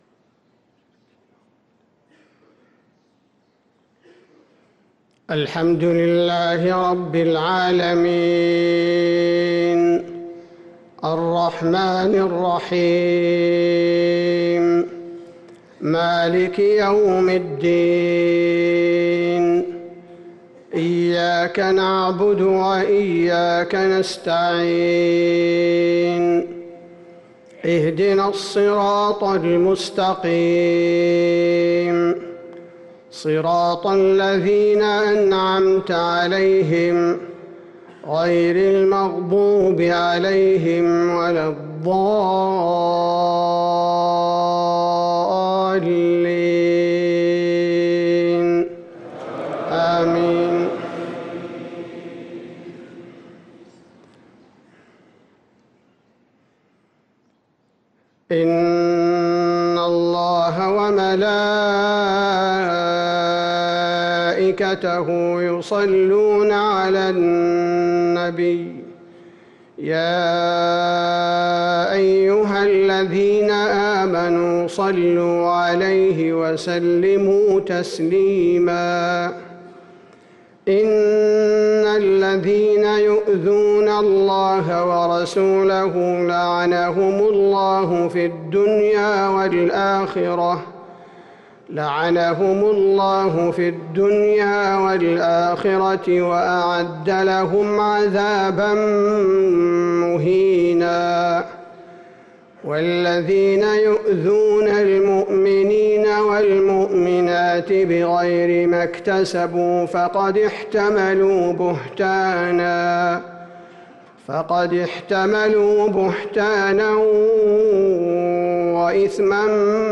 صلاة الفجر للقارئ عبدالباري الثبيتي 15 ربيع الآخر 1445 هـ
تِلَاوَات الْحَرَمَيْن .